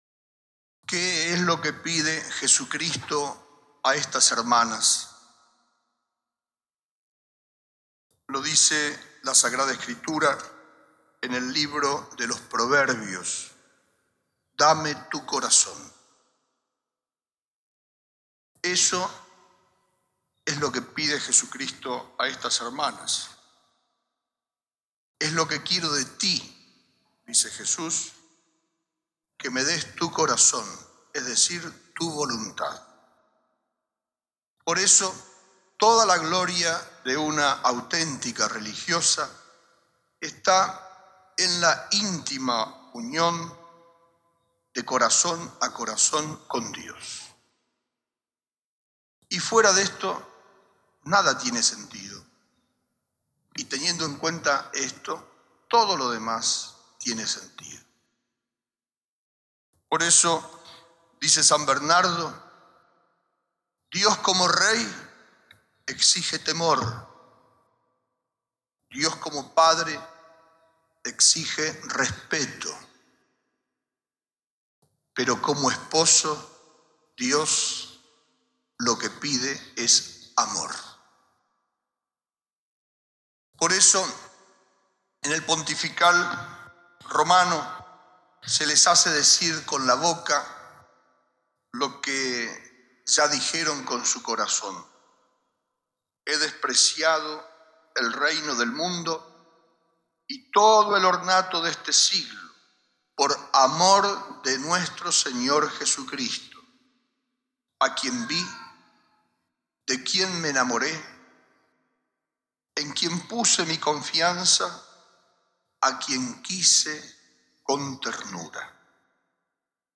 Sermon-Dame-tu-corazon-Toma-de-habito-SSVM-1992.mp3